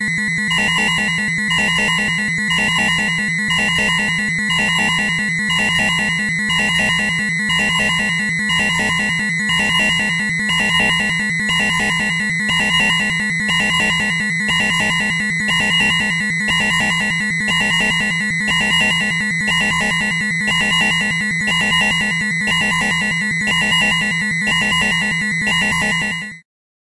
蜂鸣声 " 警报1
描述：简单的2音警报信号
标签： 警报 信号 蜂鸣
声道立体声